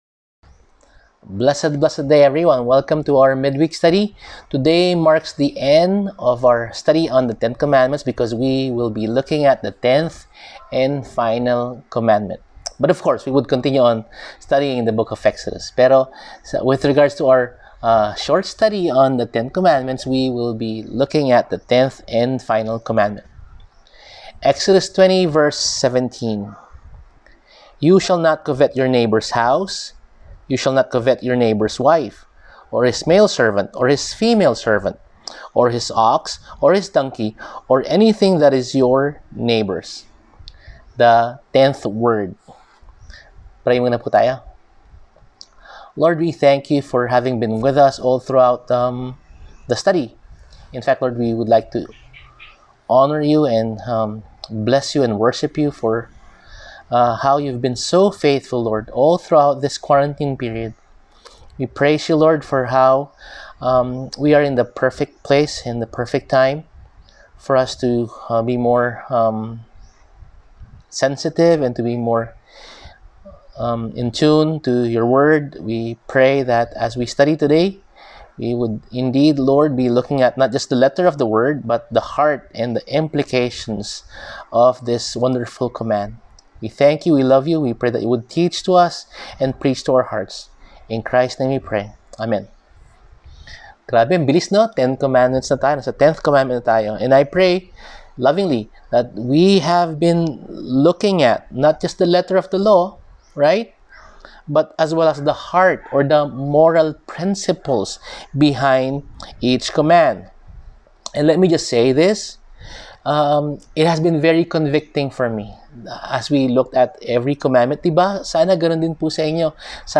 Service: Midweek